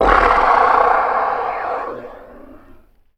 MONSTER_Breath_05_mono.wav